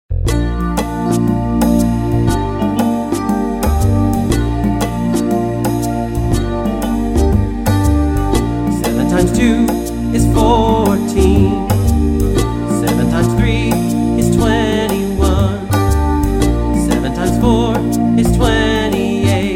Vocal mp3 Track